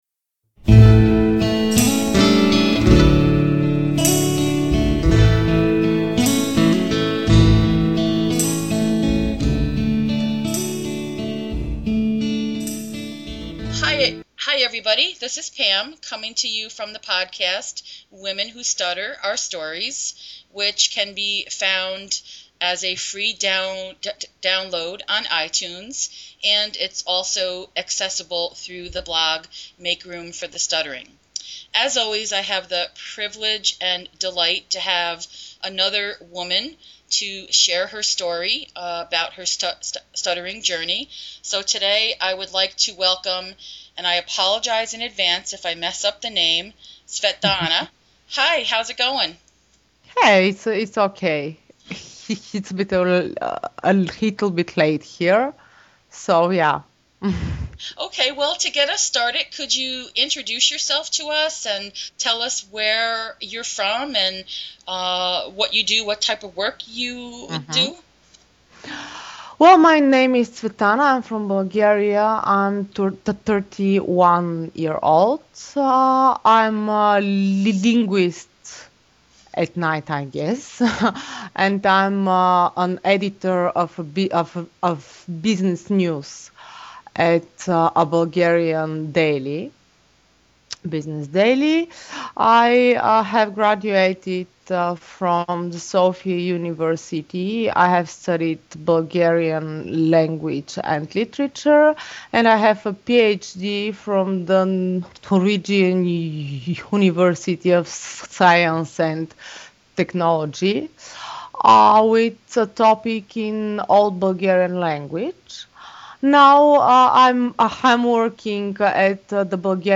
This was a great, honest conversation infused with some humor.